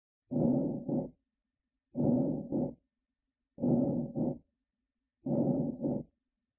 Tension_Pneumothorax.mp3